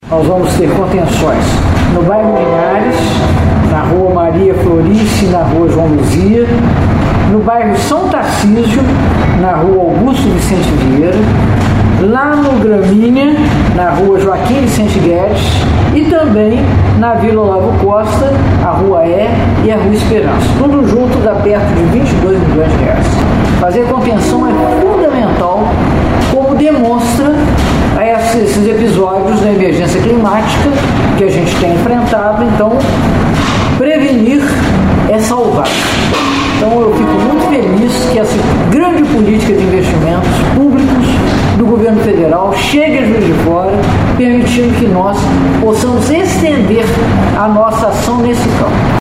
A prefeita Margarida Salomão explica a destinação do recurso do PAC Contenções.